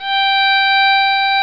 Fiddle Hi Sound Effect
fiddle-hi.mp3